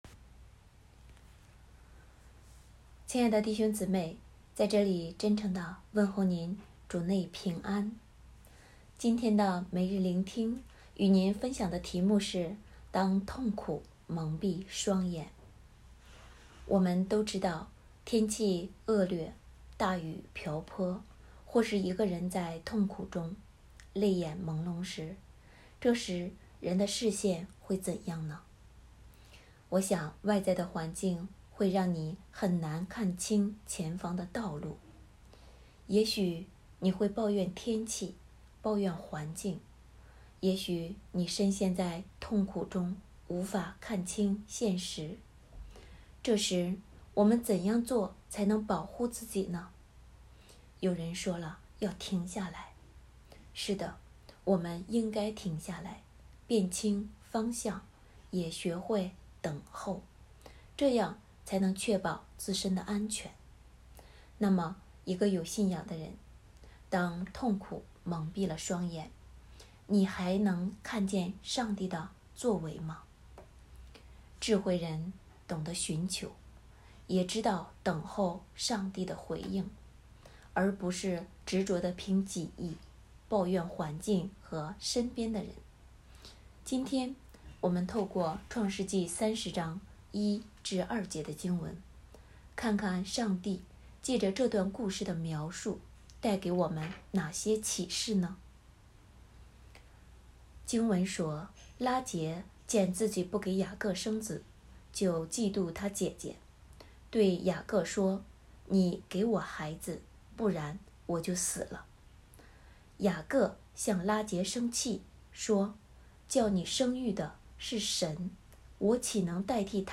生命遇见系列(1)——当痛苦蒙蔽双眼》 证道